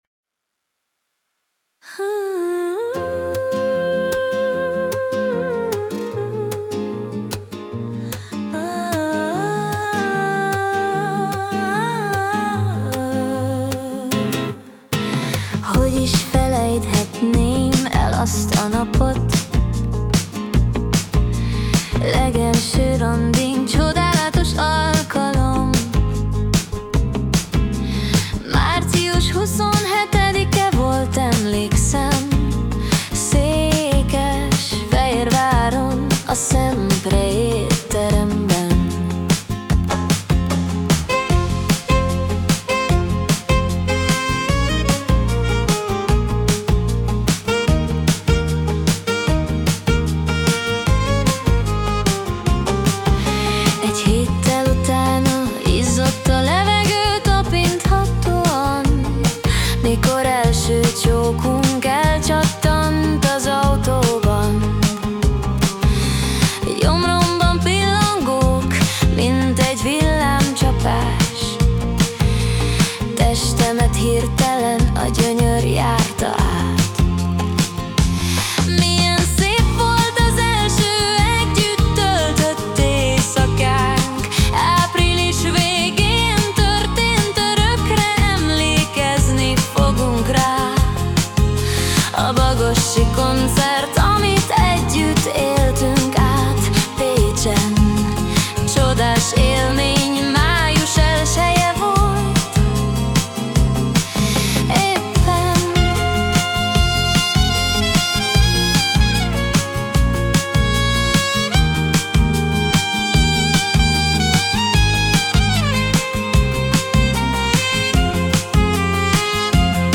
Akusztik Pop - Szülinapra
Személyre szabott ajándék dal - Alkalom: szülinapra